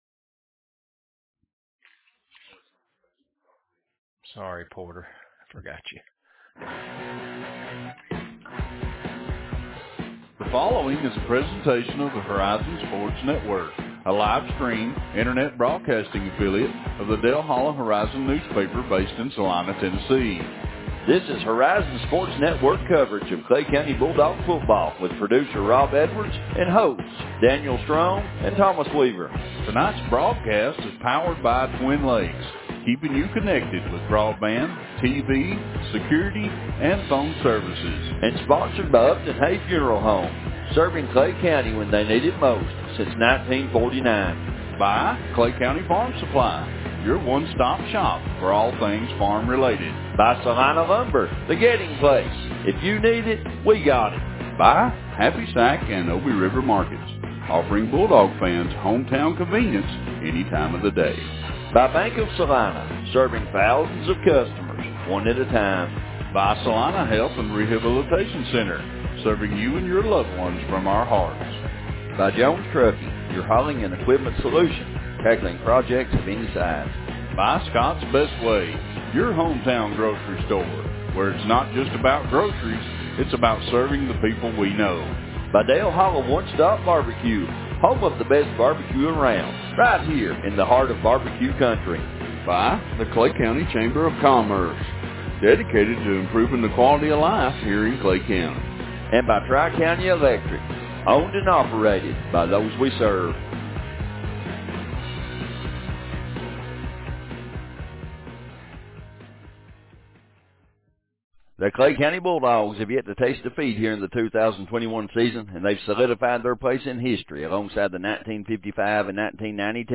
2021 Clay County High School football season broadcasts - Dale Hollow Horizon